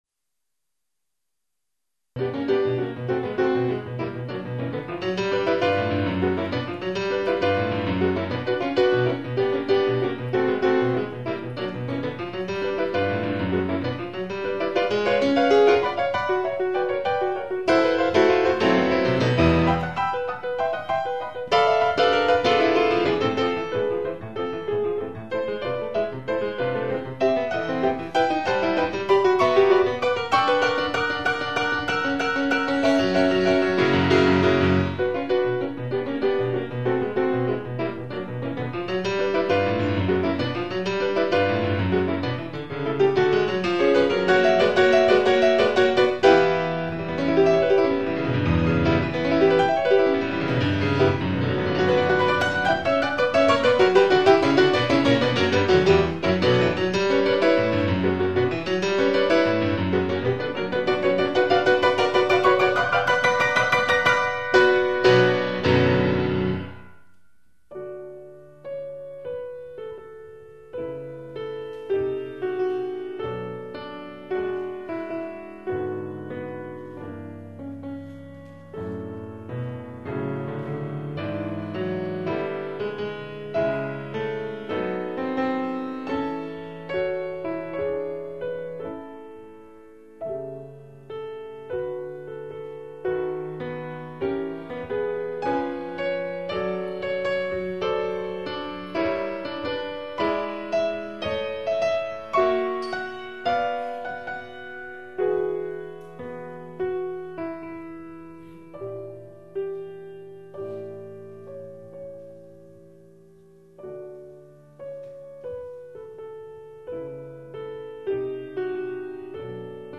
Live Recording  - Rome 1994